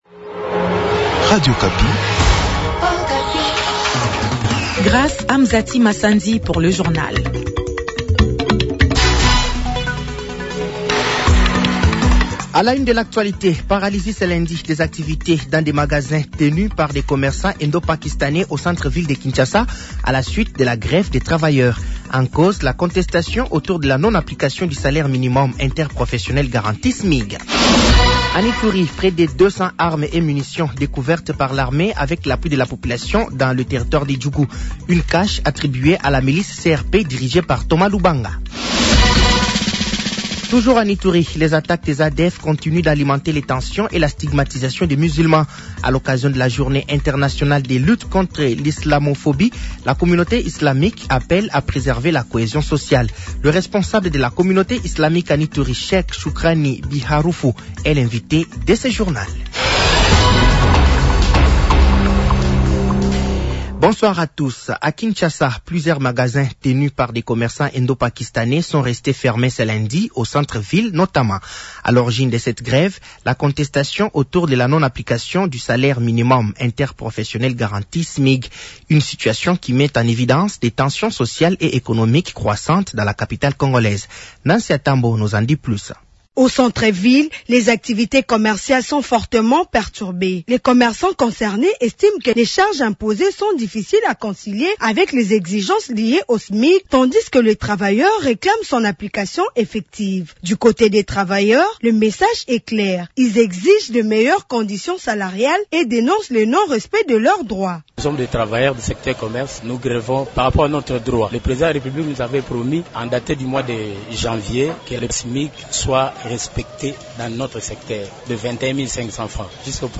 Journal français de 18h de ce lundi 23 mars 2026